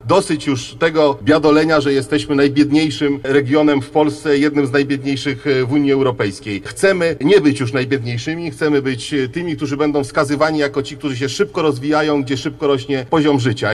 Wicepremier Jacek Sasin otworzy w Chełmie biuro poselskie. Podczas spotkania z mieszkańcami miasta Sasin dziękował za ich poparcie i zapewniał, że będzie reprezentował w Sejmie ich interesy. Wicepremier chce wspólnie z europoseł Beatą Mazurek i samorządowcami tworzyć drużynę dbającą o sprawy Chełma, Chełmszczyzny i wschodniej Lubelszczyzny.